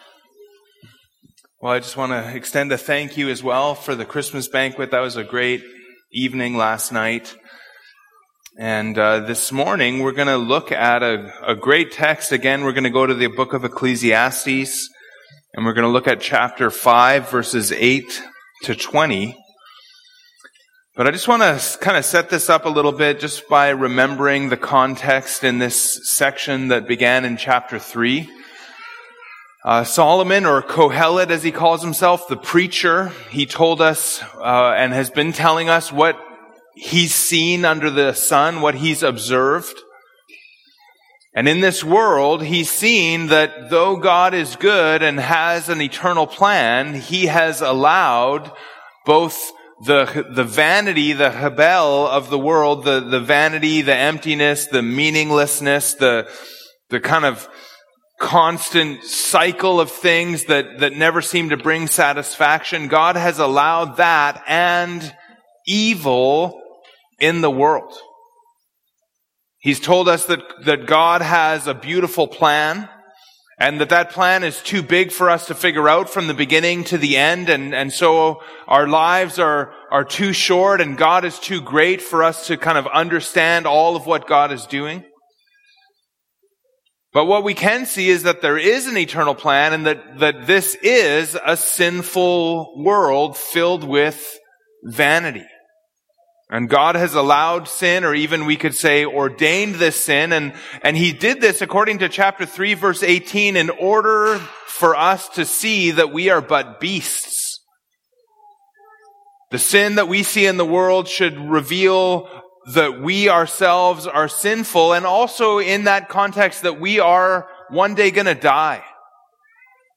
Category: Sermon Key Passage: Matthew 24:9-14